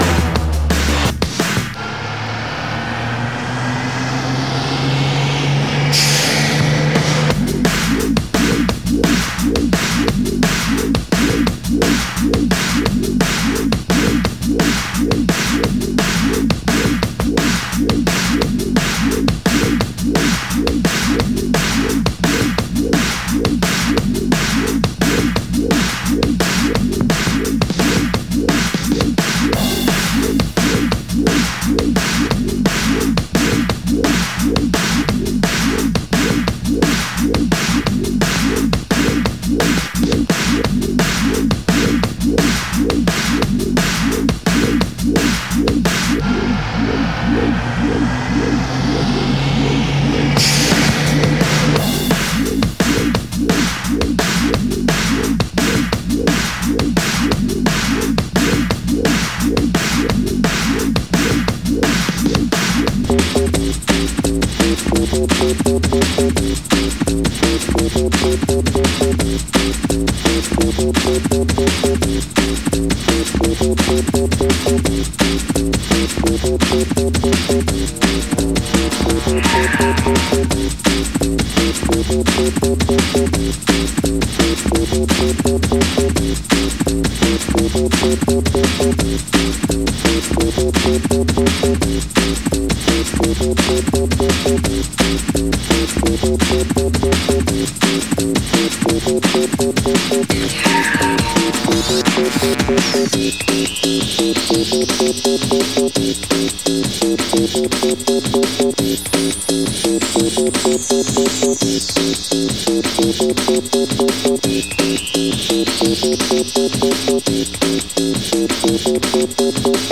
Drum N Bass